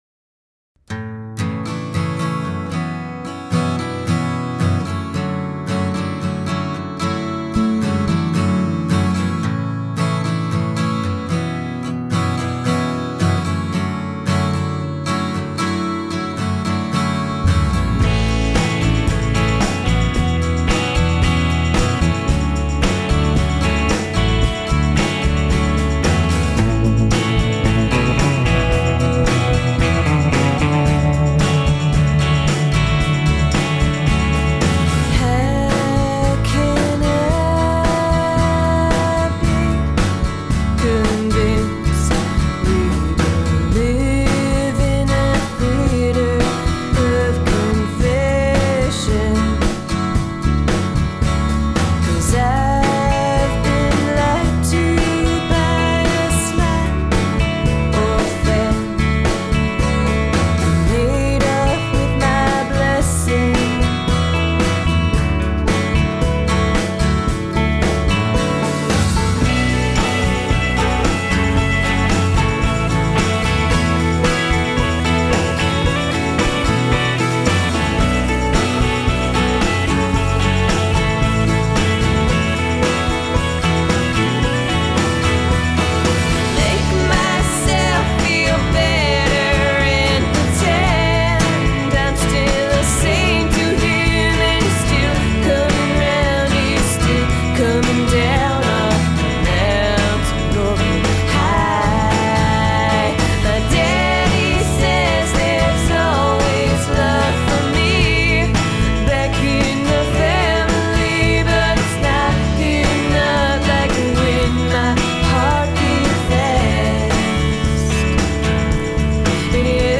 • Genre: Folk Rock